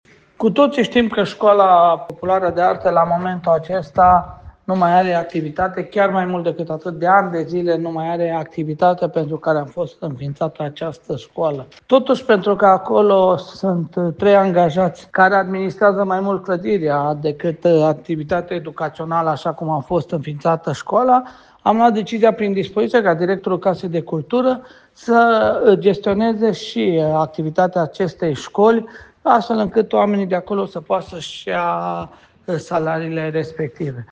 Primarul Călin Dobra explică faptul că, în lipsa unui program activ, instituția este menținută prin personalul existent, care se ocupă în principal de administrarea clădirii.